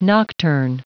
Prononciation du mot nocturne en anglais (fichier audio)
Prononciation du mot : nocturne
nocturne.wav